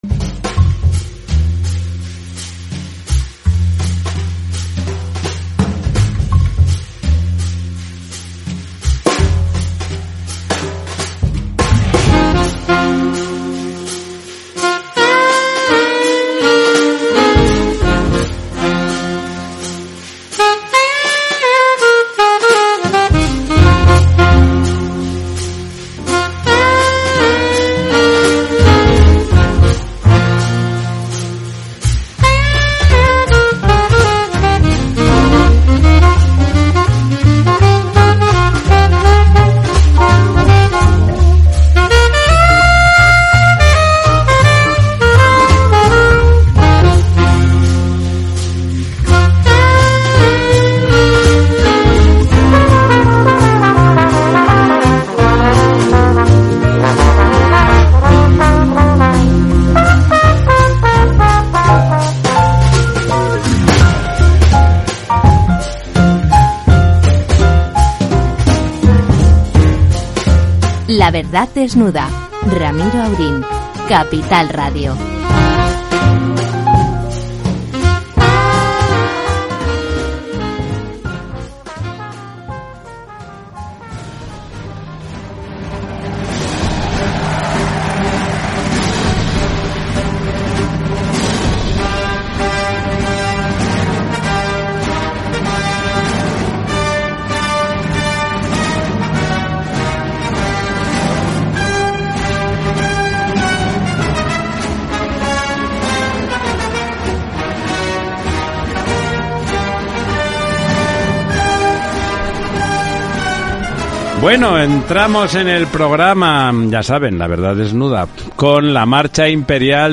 Notas de las entrevistas en este episodio: Salud confirma 11 de …